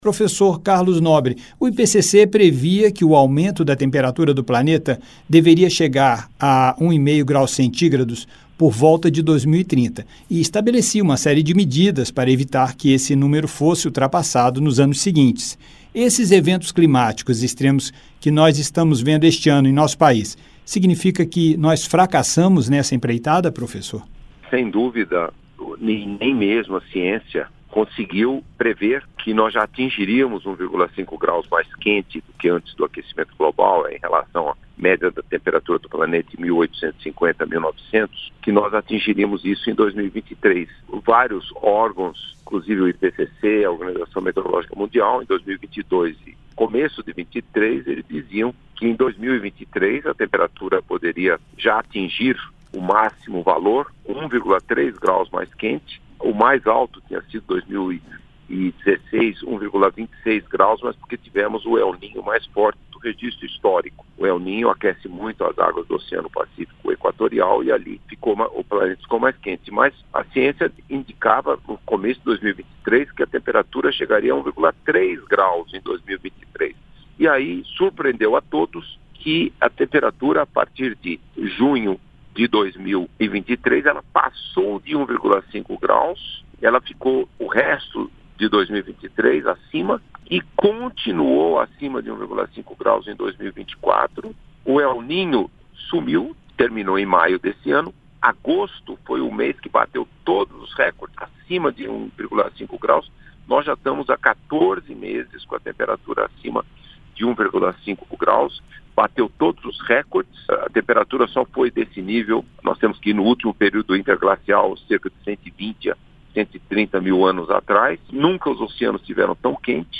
* Entrevista originalmente veiculada em 19/09/2024.